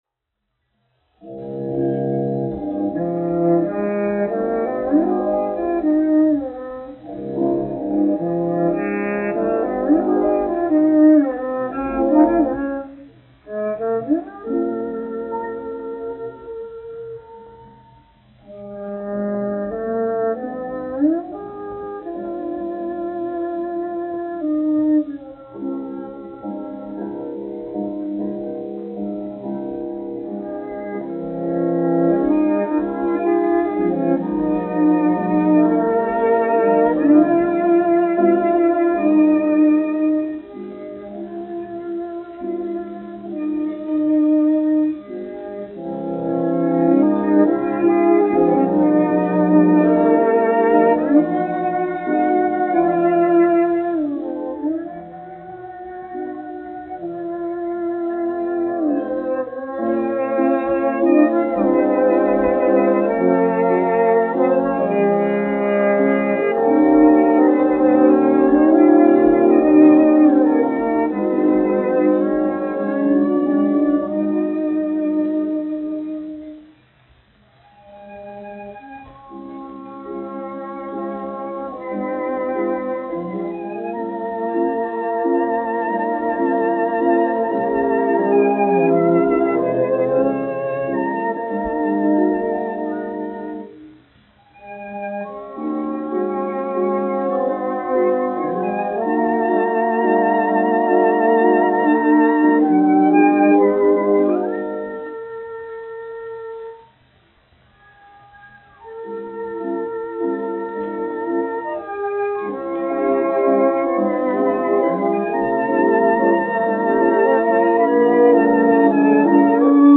1 skpl. : analogs, 78 apgr/min, mono ; 25 cm
Kamermūzika
Klavieru trio
струнный квартет
Atskaņojumā piedalās klavieru trio.
Skaņuplate